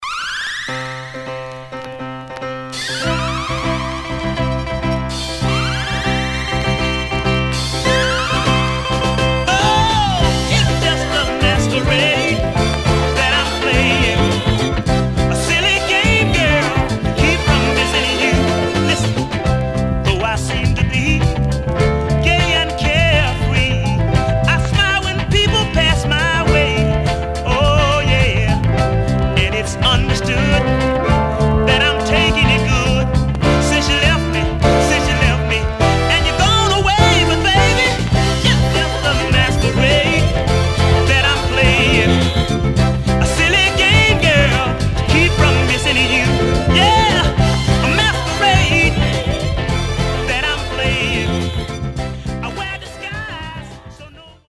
Some surface noise/wear
Mono
Soul